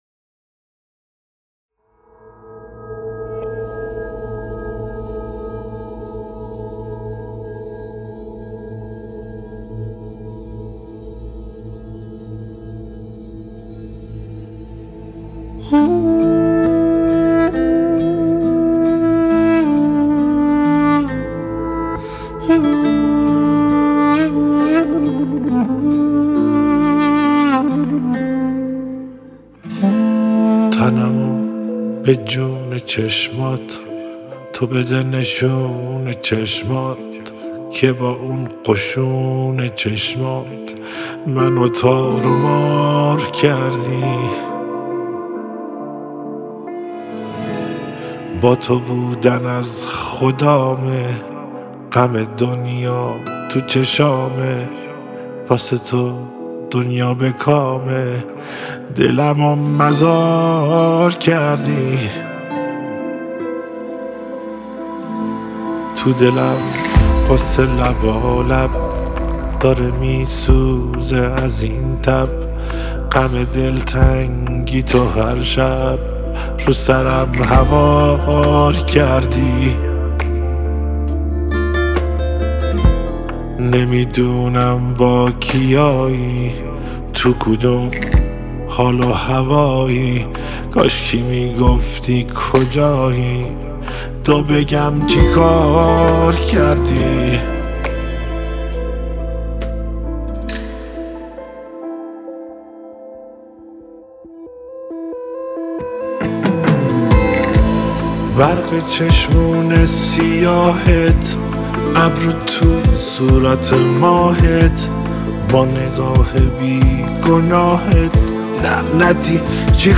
دانلود آهنگ غمگین و سوزناک